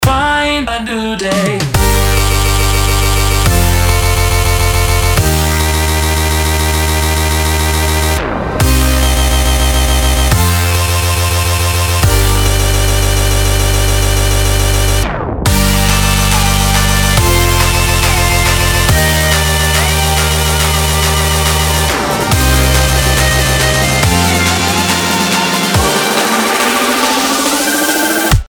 • Качество: 320, Stereo
громкие
Электро